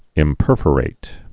(ĭm-pûrfər-ĭt)